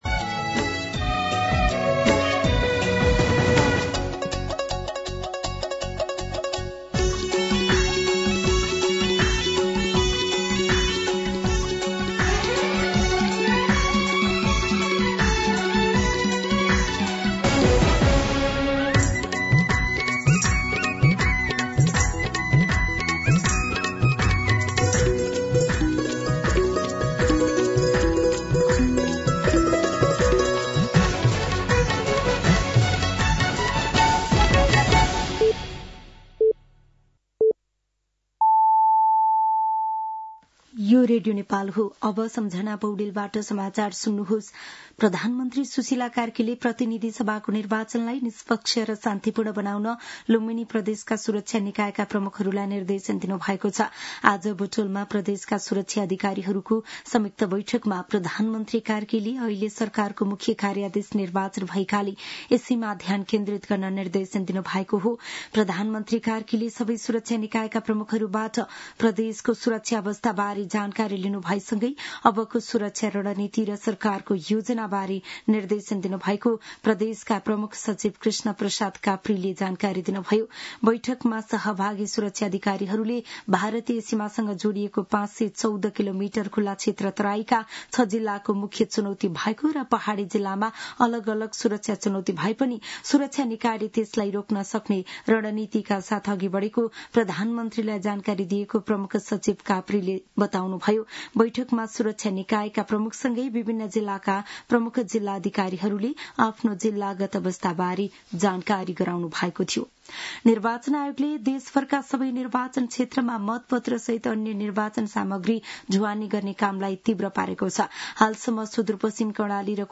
दिउँसो ४ बजेको नेपाली समाचार : ९ फागुन , २०८२
4pm-Nepali-News-2.mp3